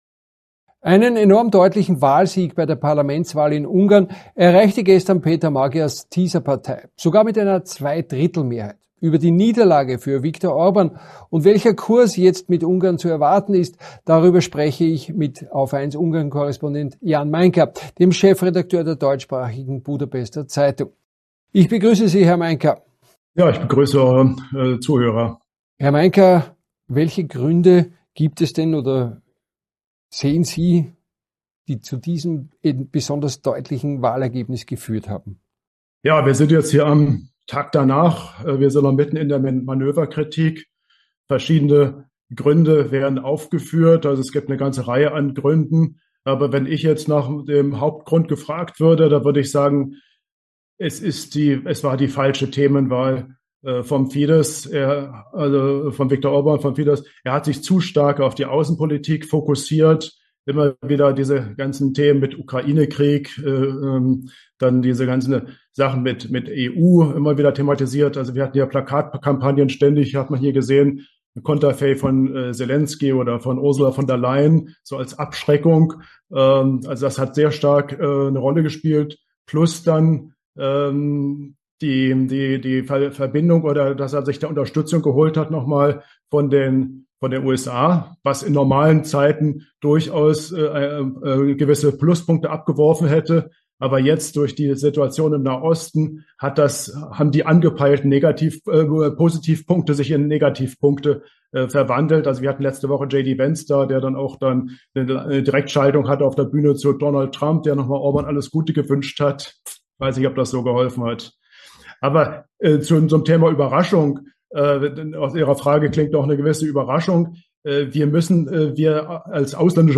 im Interview bei